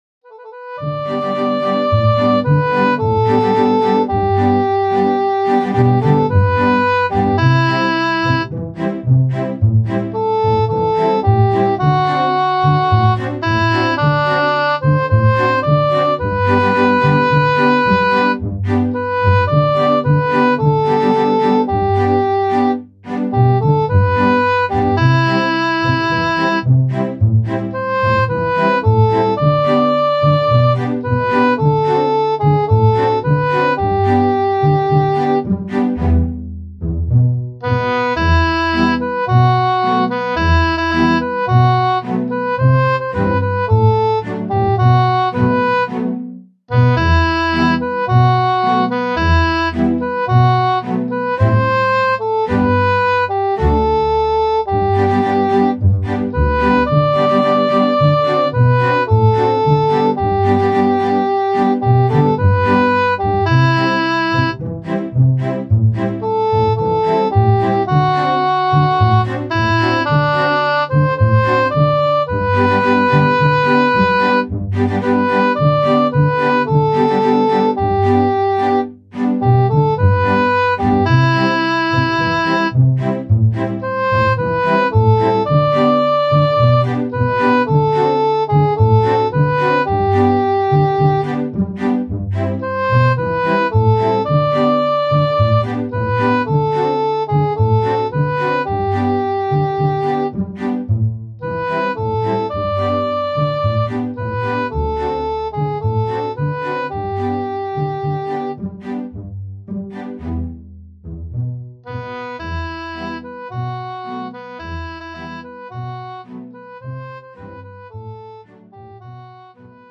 Mehrstimmige Melodien mit wundersamen Klängen